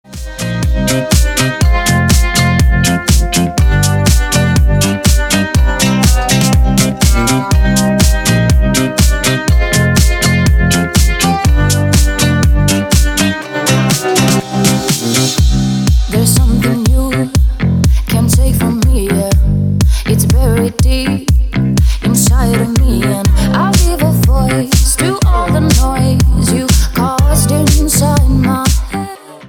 • Качество: 320, Stereo
женский вокал
мелодичные
энергичные
Стиль: deep house